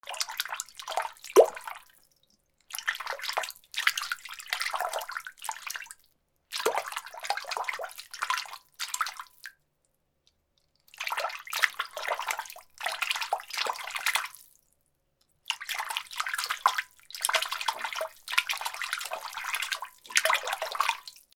/ M｜他分類 / L30 ｜水音-その他
細い棒で水をかきまぜる
『パチャパチャ』